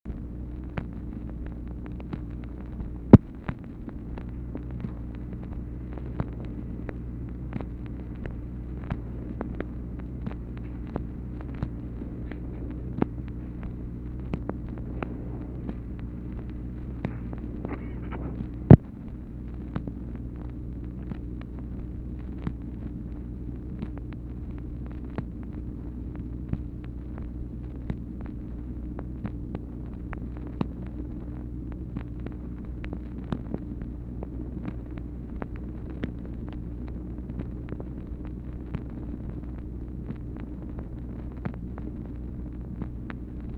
MACHINE NOISE, January 1, 1964
Secret White House Tapes | Lyndon B. Johnson Presidency